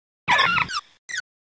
Archivo:Grito de Budew.ogg
== Licencia == {{Archivo de audio}} Categoría:Gritos de Pokémon de la cuarta generación